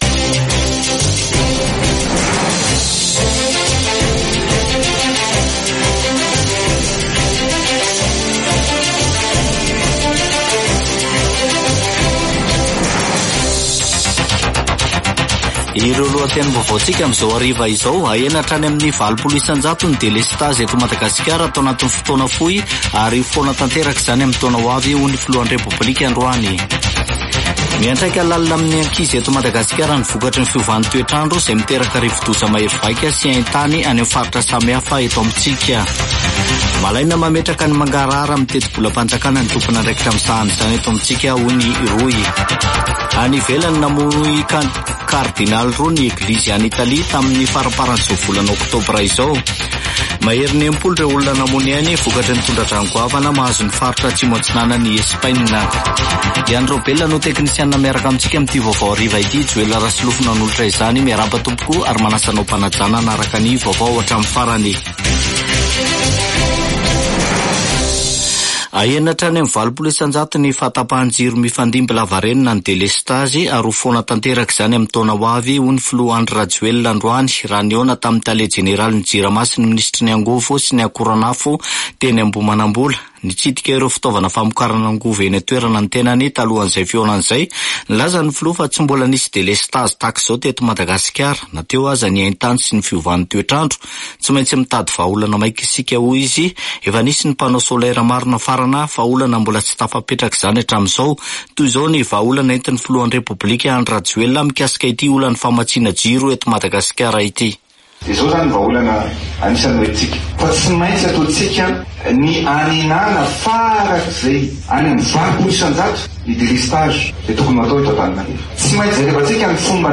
[Vaovao hariva] Alarobia 30 ôktôbra 2024